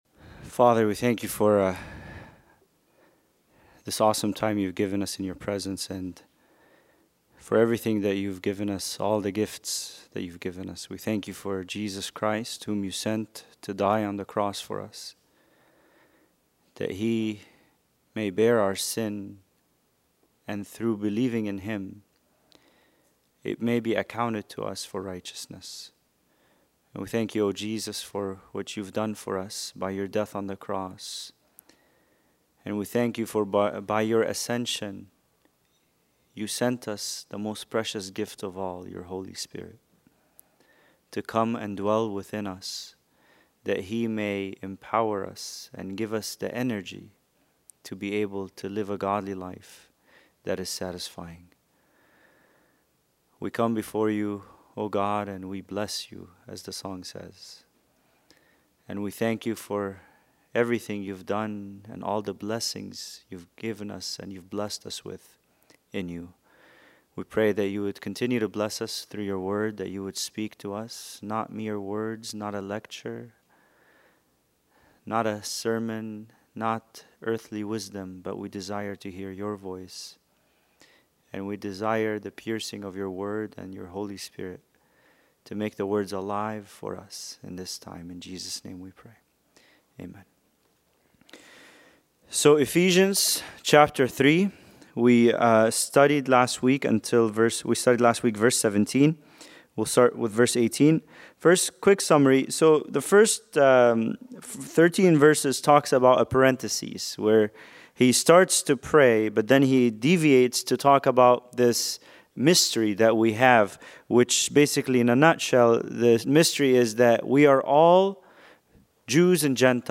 Bible Study: Ephesians 3:18-21